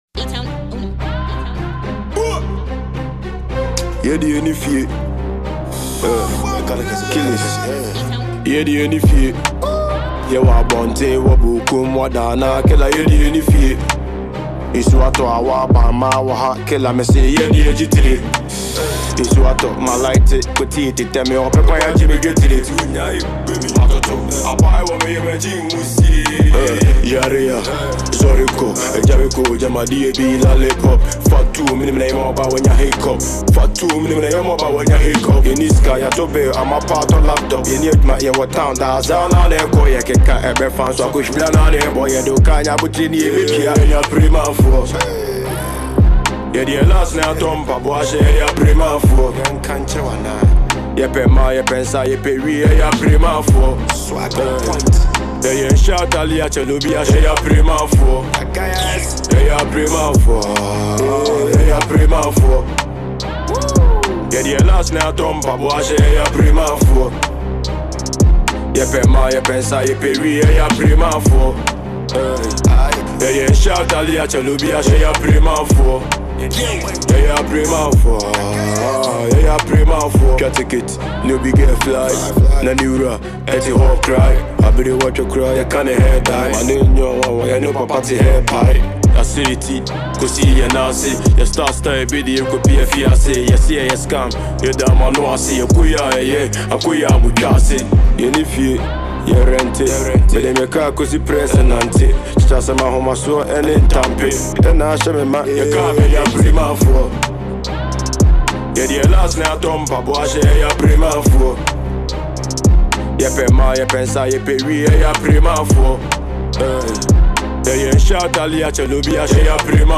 Genre: Asakaa Music